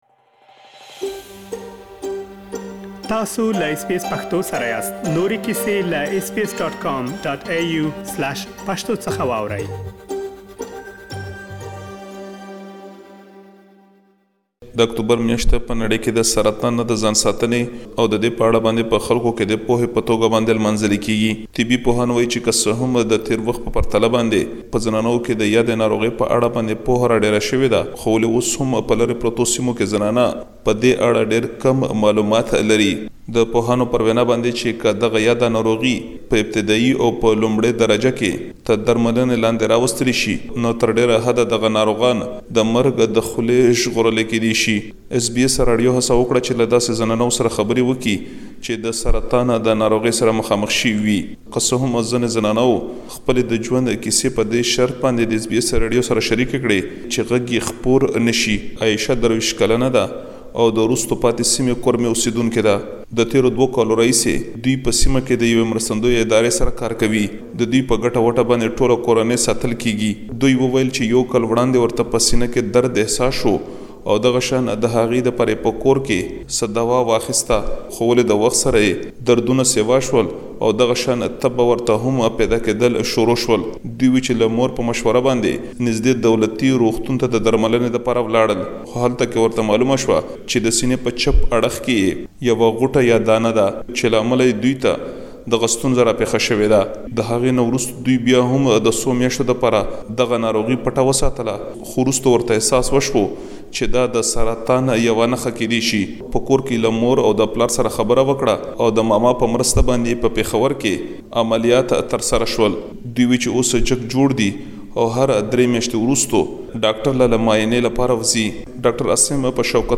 په پيښور کې له ځينو ډاکټرانو او سينې سرطان ناروغانو سره خبرې کړي چې دا ټول پدې رپوټ کې اوريدلی شئ.